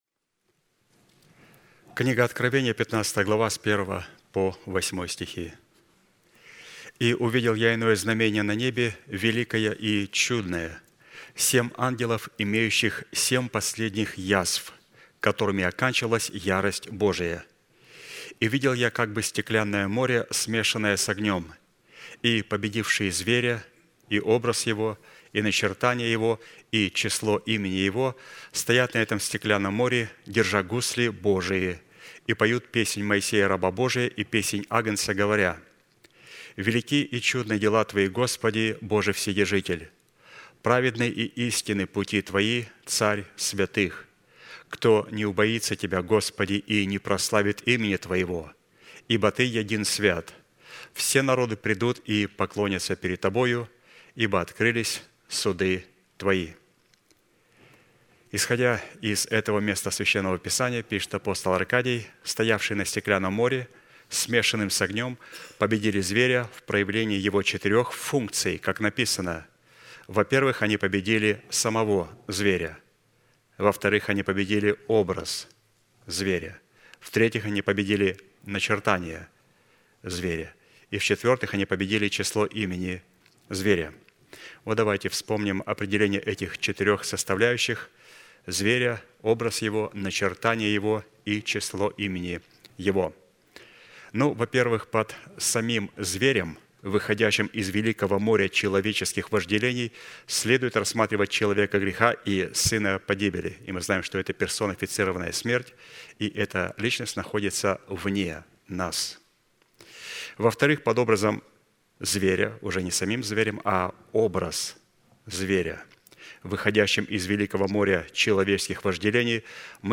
Служение: Воскресенье